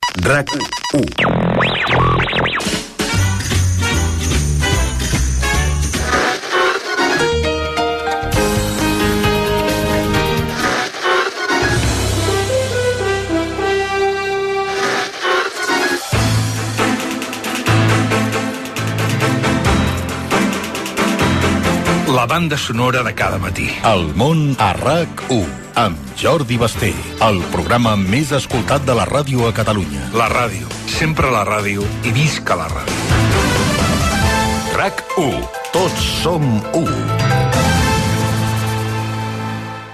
Identificació de l'emissora i promoció del programa feta amb sintonies d'alguns programes matinals de ràdio d'altres emissores